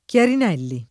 [ k L arin $ lli ]